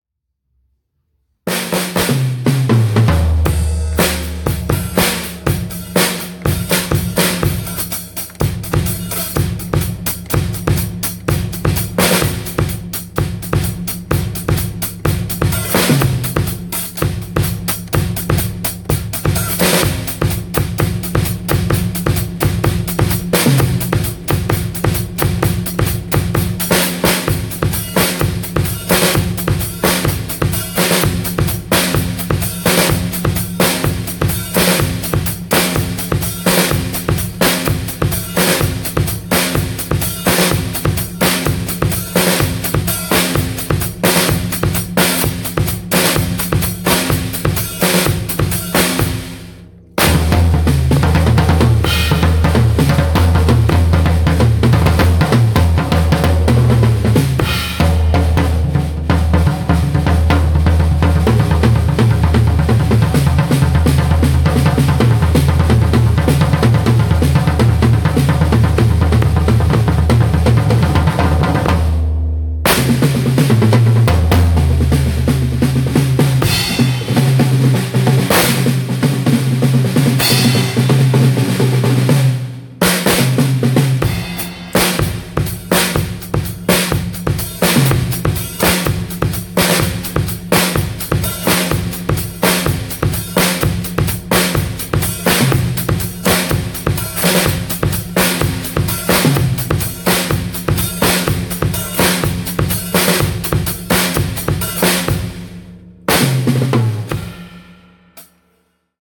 Which is why, last week, after a gap of over five years, I picked up my drumsticks and went to a jam room in South Extension where there’s a nice drum set, and I practiced playing the drums for an hour.
I was rusty, stiff in bone and muscle and brain, I panted and gasped at the exertion, I missed a beat every nine beats on average.
I managed to record part of my solo cacophony, and place three short sections below – missed beats and all – for your torment, ferment and comment, O patient and long-suffering reader!
Don’t worry, I promise you I won’t post any more of my solo practice sessions.